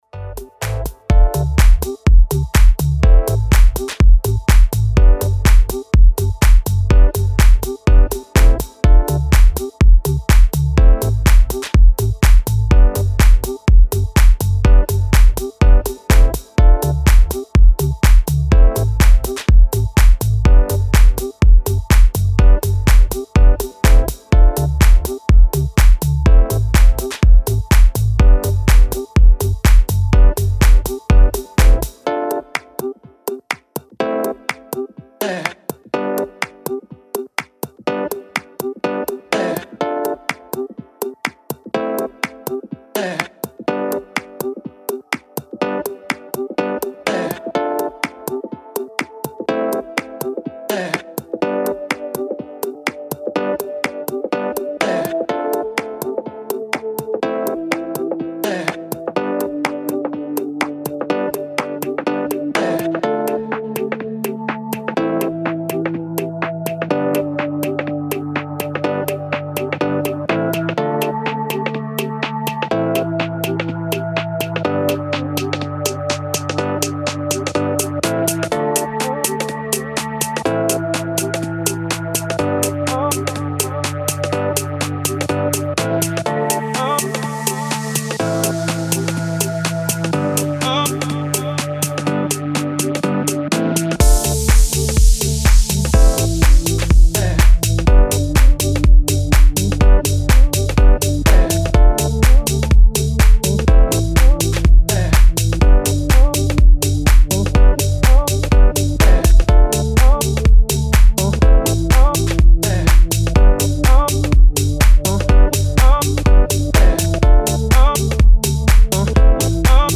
Style: Tech House / House